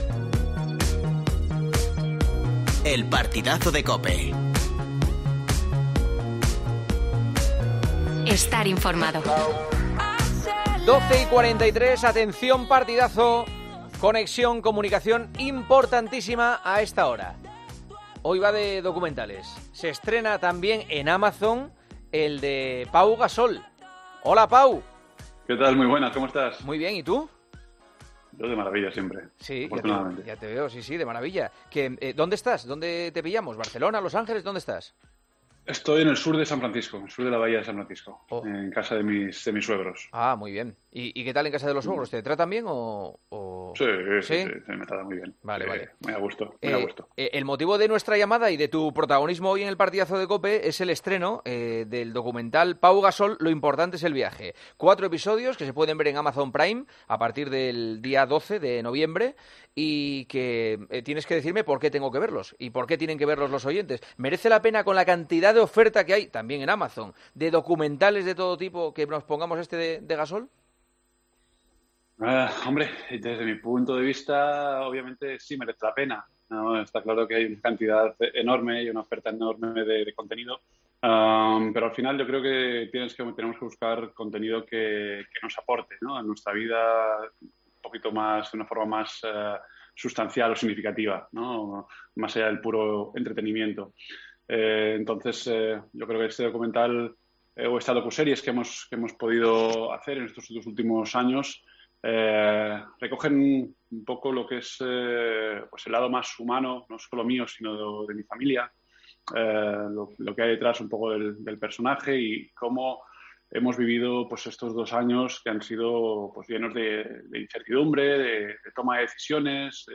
Juanma Castaño habló con Pau Gasol sobre el documental que cuenta sus dos últimos años de carrera: 'Pau Gasol. Lo importante es el viaje'.